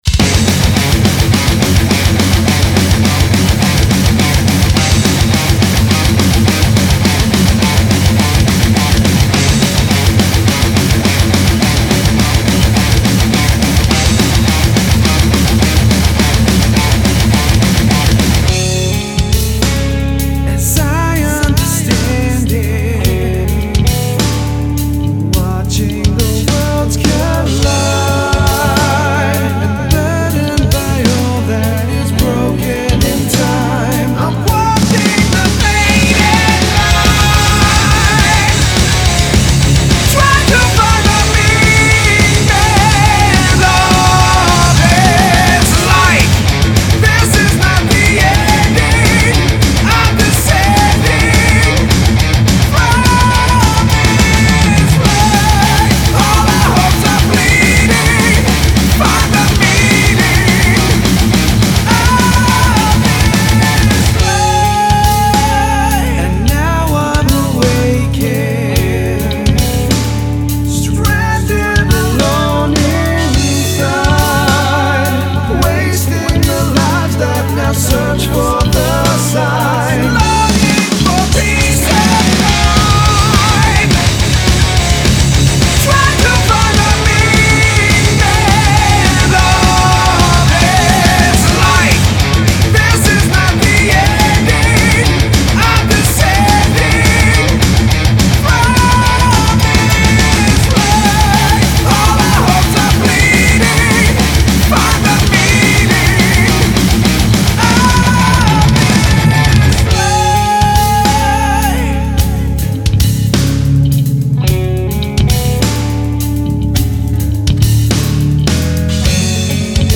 Australian power/thrash metallers
vocals, rhythm guitar
lead guitar
bass
drums